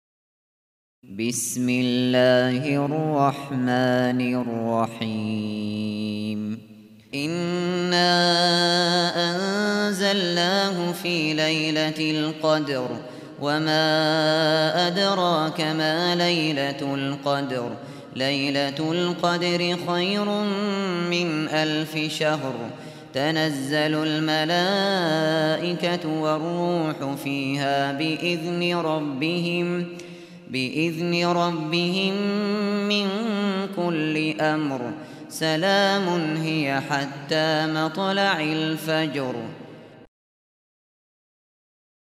Сура “Кадр” в исполнении Абу Бакра аш-Шатри